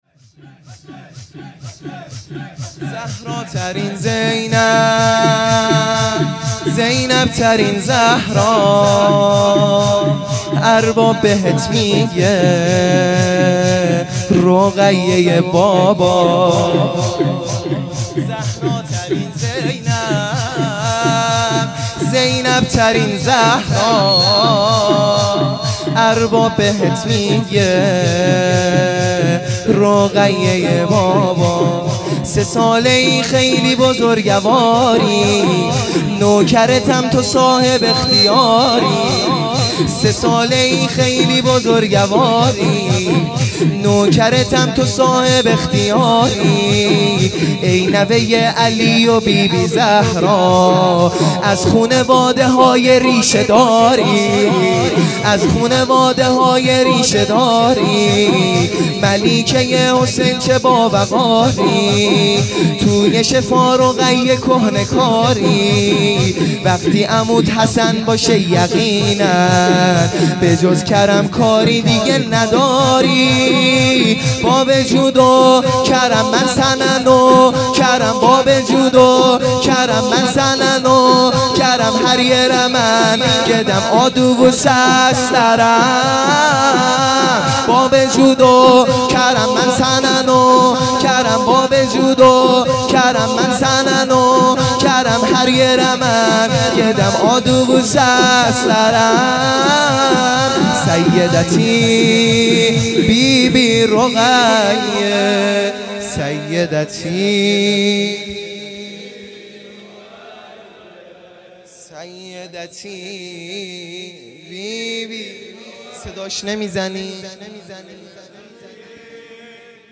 شور | سه ساله ای خیلی بزرگواری